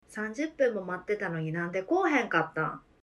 We have teachers from all over Japan and they were kind enough to record their native accents.
Kansai Dialect:
Sentense-1-Kansai.mp3